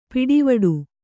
♪ piḍivaḍu